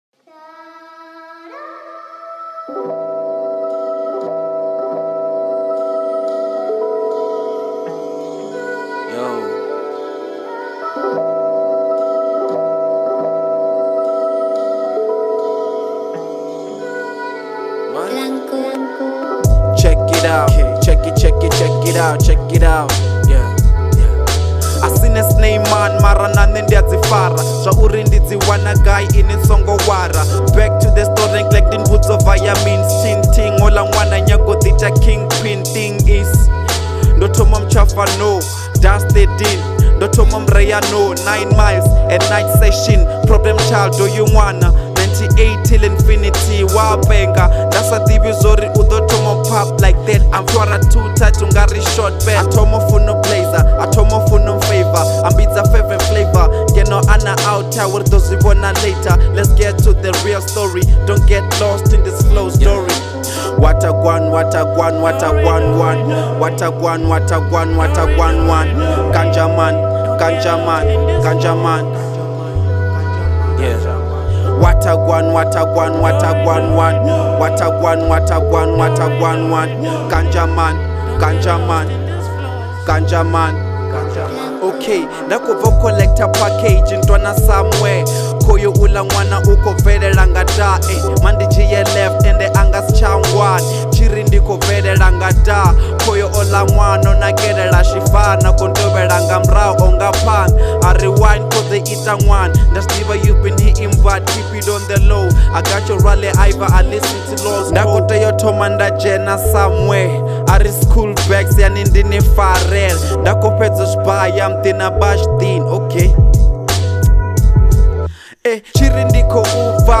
03:16 Genre : Venrap Size